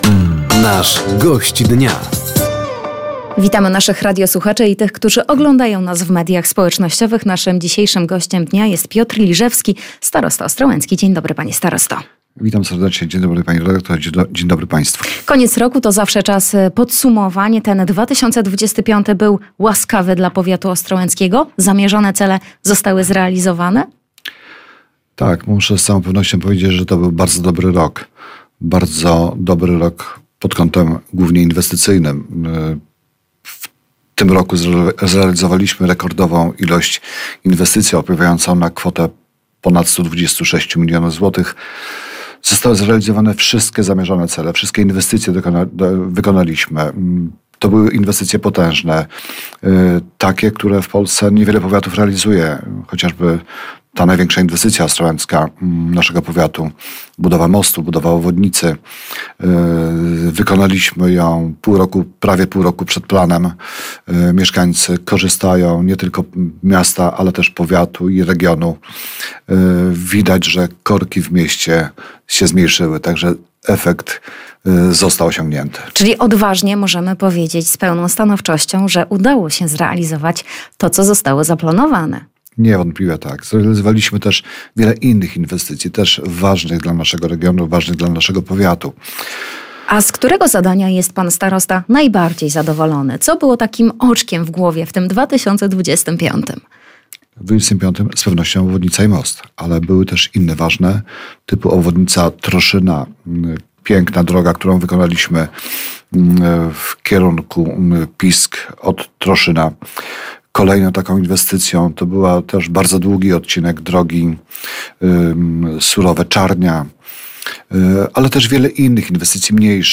Gościem Dnia Radia Nadzieja był Piotr Liżewski, starosta powiatu ostrołęckiego. Tematem rozmowy były kluczowe inwestycje zrealizowane w mijającym roku oraz plany rozwoju powiatu.